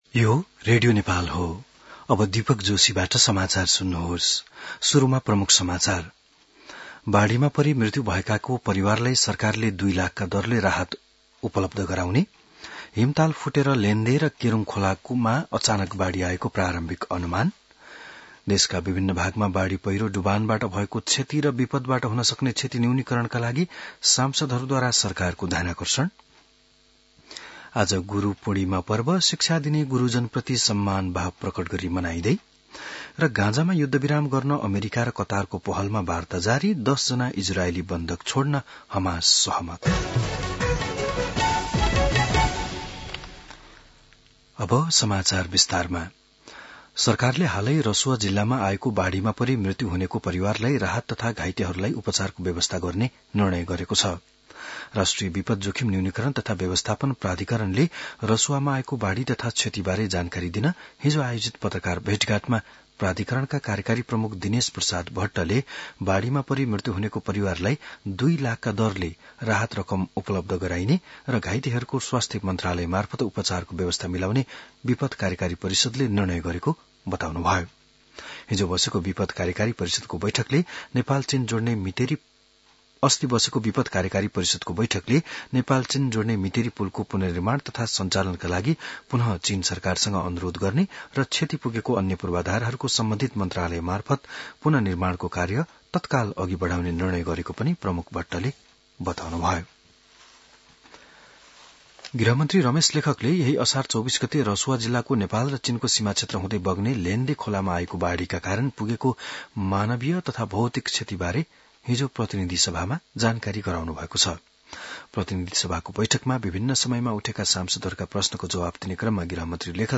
बिहान ९ बजेको नेपाली समाचार : २६ असार , २०८२